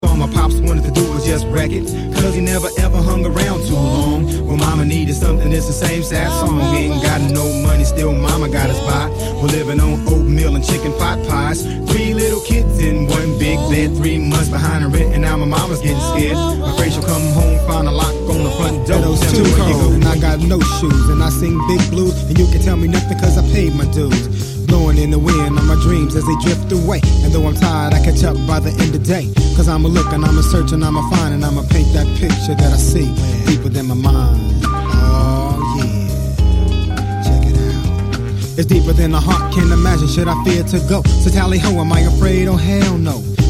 Bay Area hip-hop